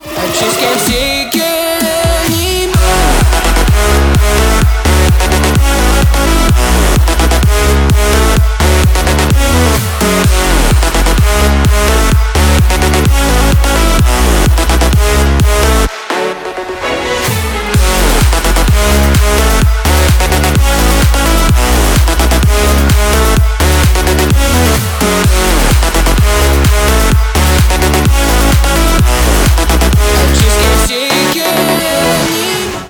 мужской вокал
dance
EDM
Big Room
progressive house